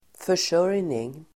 Uttal: [för_s'ör:jning]